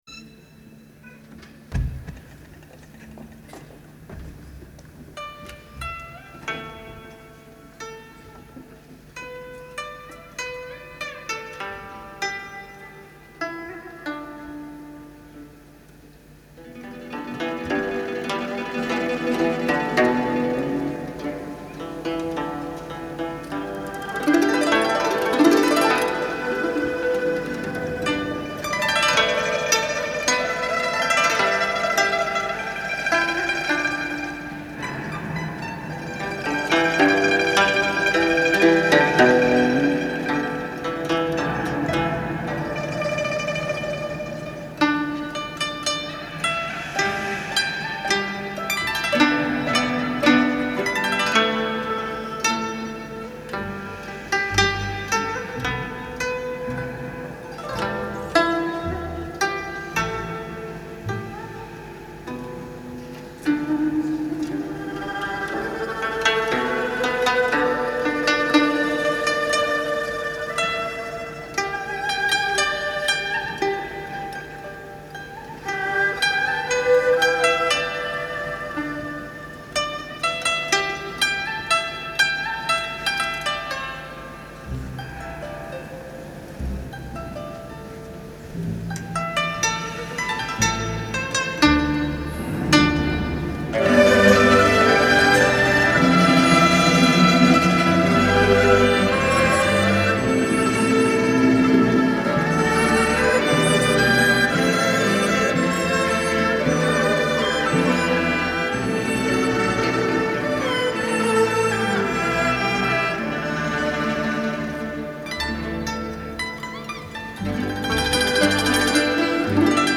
Genre : Techno